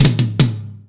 ROOM TOM2C.wav